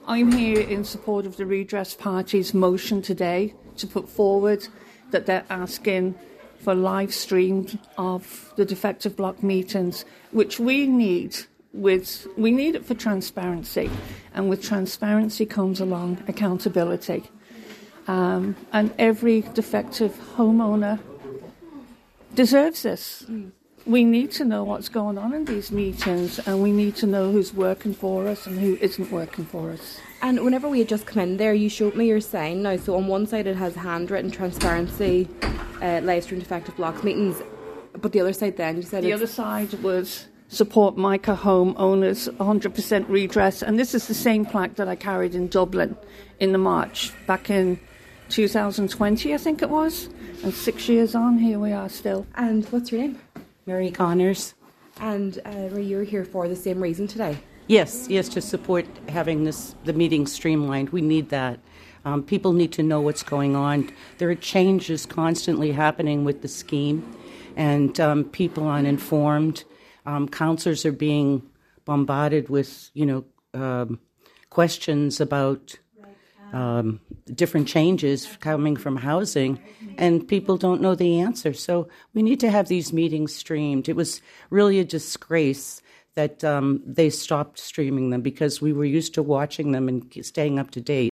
Two homeowners affected by defective concrete are demonstrating outside Donegal County Council offices in Lifford this morning, as the first plenary meeting of the year gets underway.